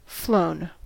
Uttal
Uttal US: IPA : /ˈfloʊn/ UK: IPA : /ˈfləʊn/ Ordet hittades på dessa språk: engelska Ingen översättning hittades i den valda målspråket.